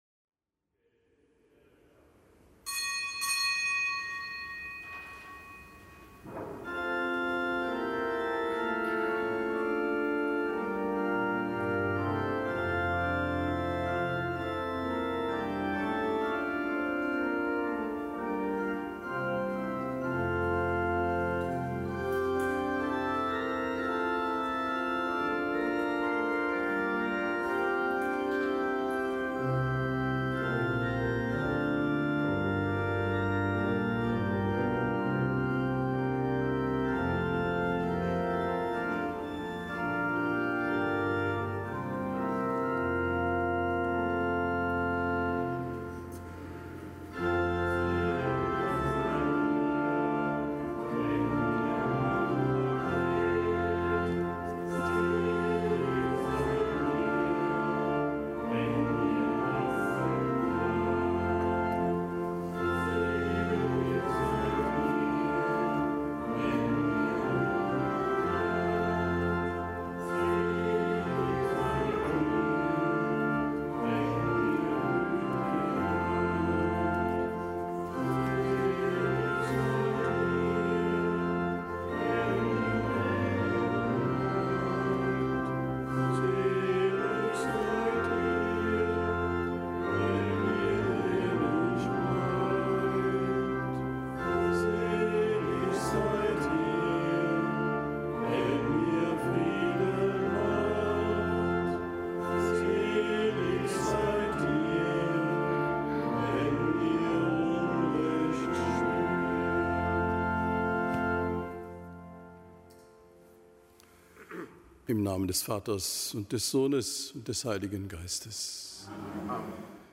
Kapitelsmesse aus dem Kölner Dom am Gedenktag des Heiligen Ignatius von Loyola, Priester, Ordensgründer.